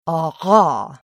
Fricativa epiglottale sonora